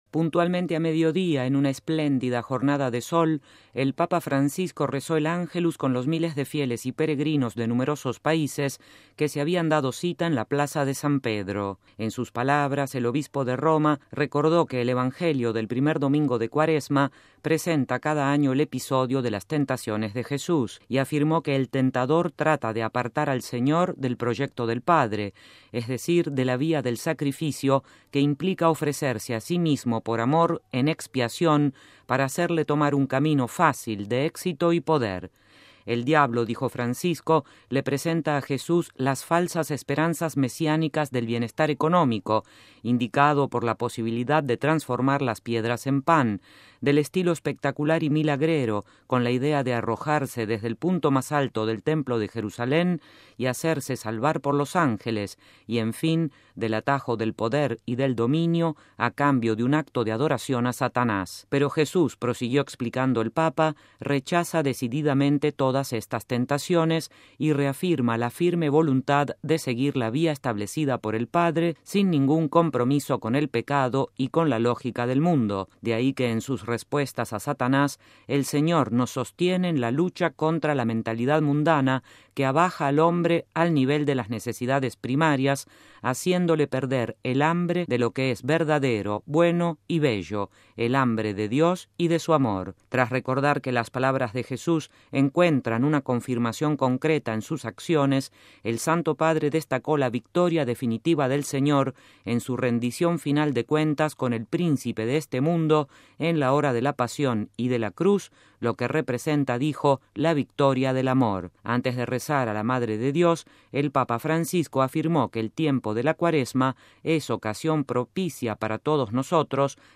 MP3 Puntualmente a mediodía, en una espléndida jornada de sol, el Papa Francisco rezó el Ángelus con los miles de fieles y peregrinos de numerosos países que se habían dado cita en la Plaza de San Pedro.